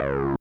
synth hit.wav